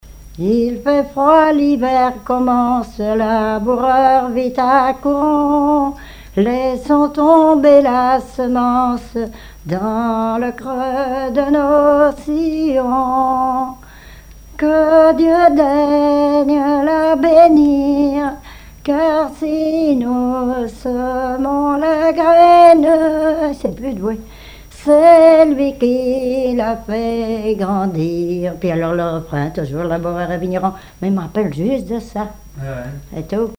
Mémoires et Patrimoines vivants - RaddO est une base de données d'archives iconographiques et sonores.
Bribes de chansons ou cantiques
Pièce musicale inédite